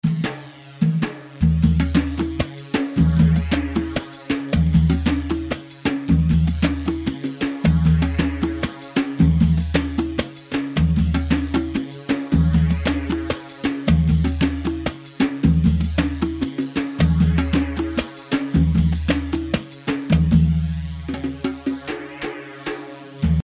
Bandari 4/4 154 بندري
Bandari-4-4-154.mp3